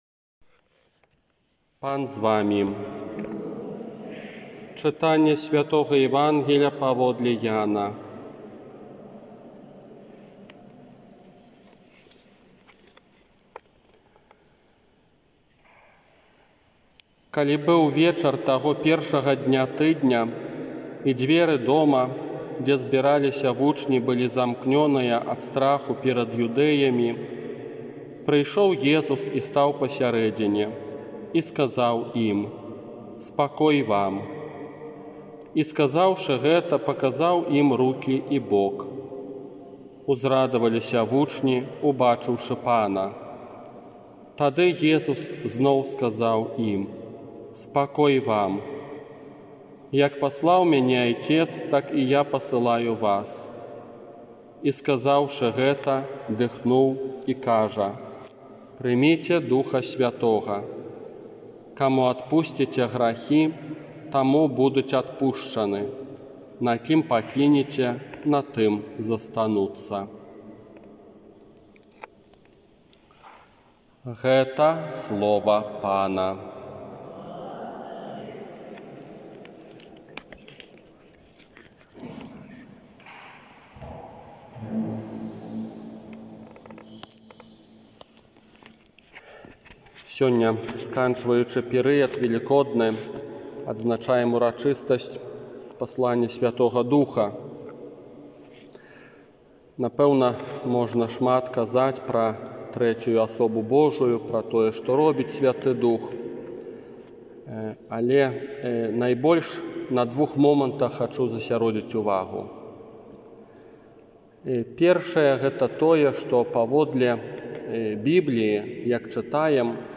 ОРША - ПАРАФІЯ СВЯТОГА ЯЗЭПА
Казанне на Ўрачыстасць Спаслання Духа Святога 23 траўня 2021 года